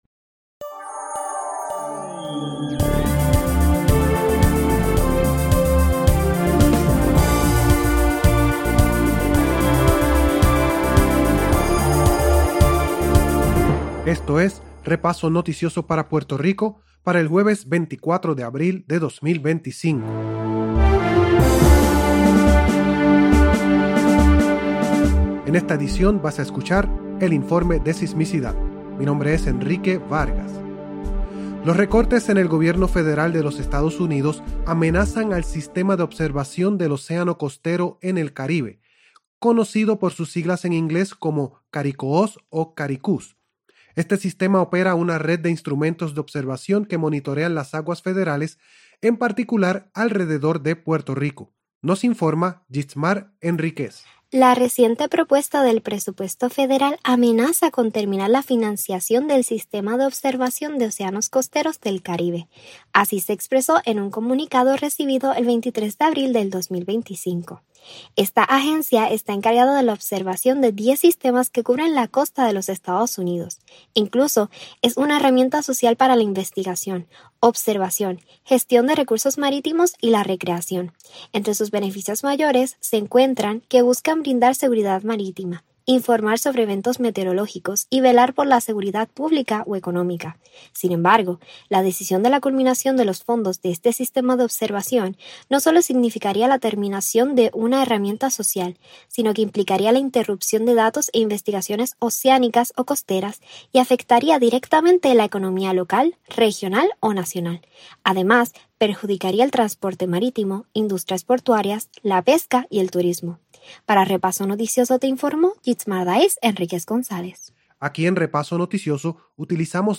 Repaso Noticioso es un podcast que presenta informes y noticias sobre Puerto Rico. Prestamos una atención especial a los temas de aire, agua, seguridad alimentaria, albergue, demografía y desperdicios. También presentamos reportajes, especiales y crónicas de actualidad.